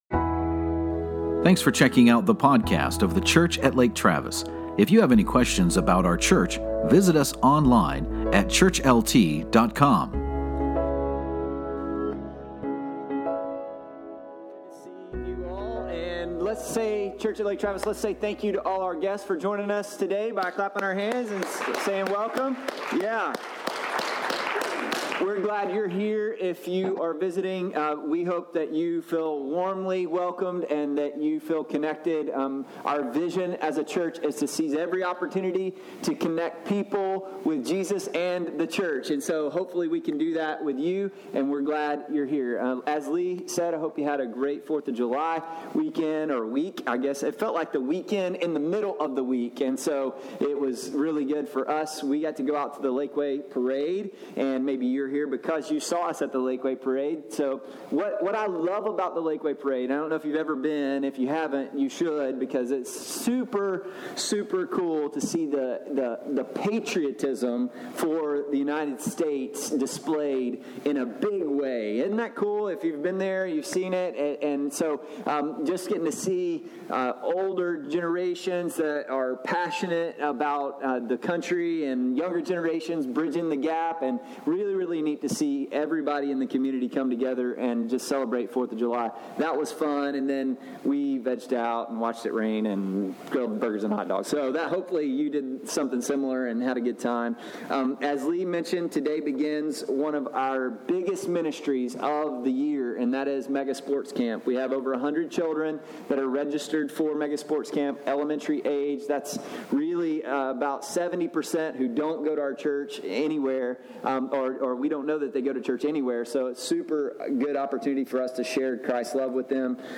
***The first 9:00 minutes is a mid year update for what God has accomplished through the Church at Lake Travis. The message begins at 9:03 Everyone is a worshiper. We all have people or things that we love, sacrifice for, and are our number one affection.